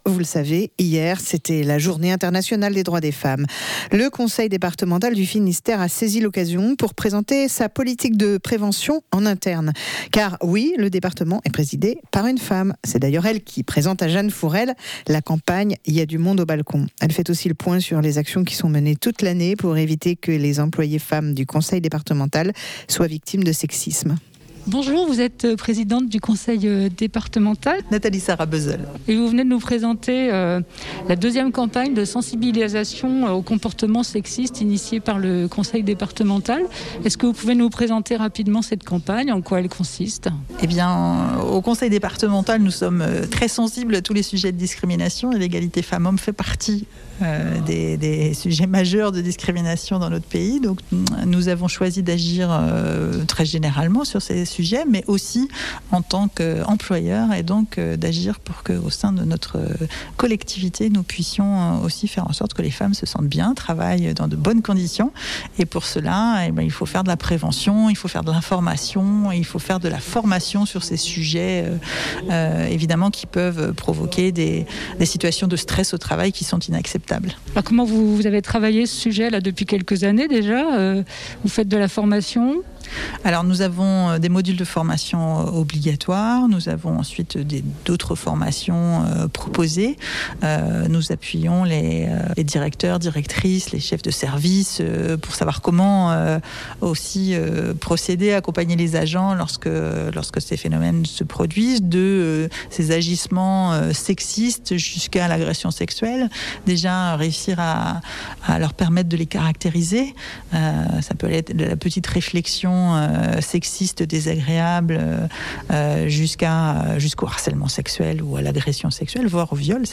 Réécoutez l'interview de Nathalie Sarabezolles, présidente du Conseil départemental du Finistère